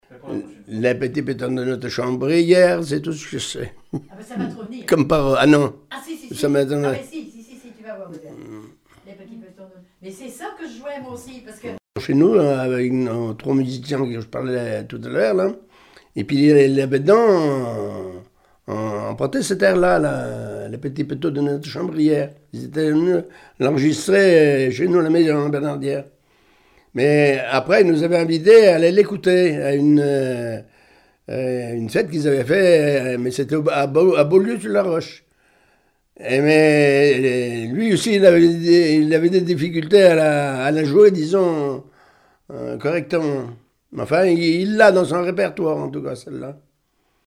danse : branle : avant-deux ;
Pièce musicale inédite